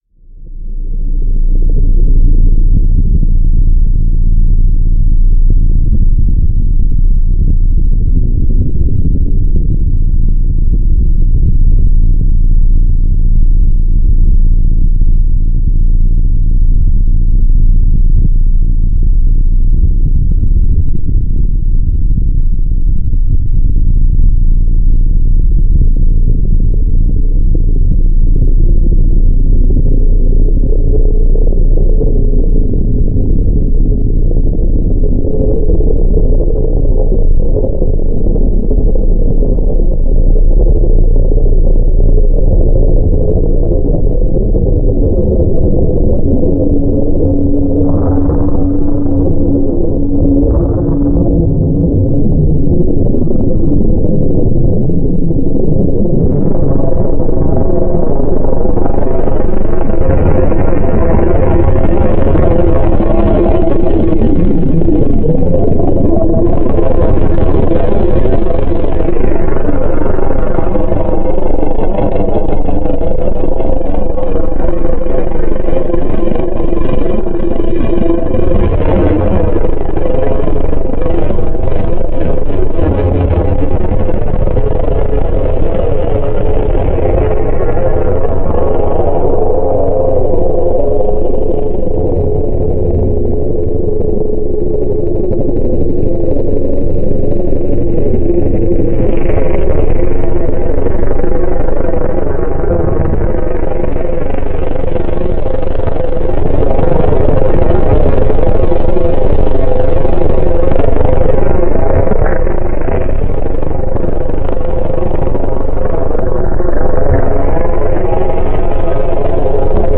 synth analogici, synth digitali, loop, campionamenti
musica elettronica